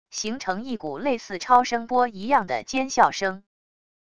形成一股类似超声波一样的尖啸声wav音频